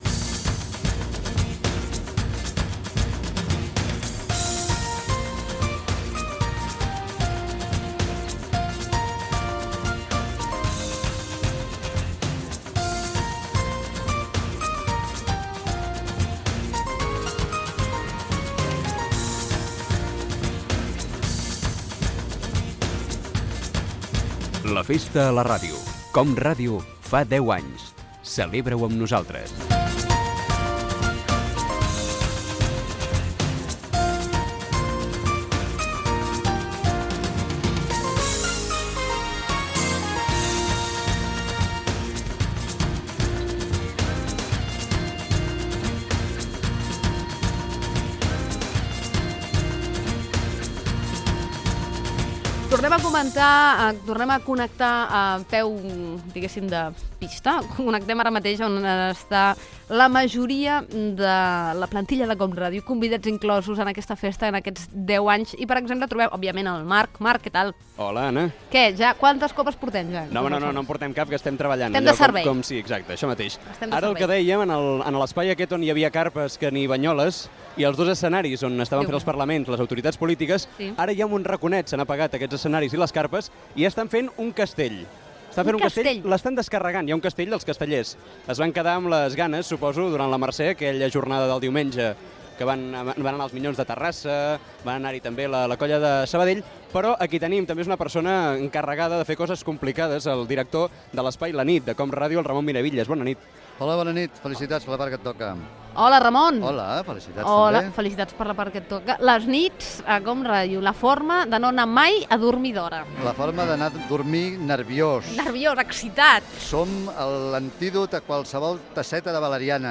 Entreteniment
Fragment extret de l'arxiu sonor de COM Ràdio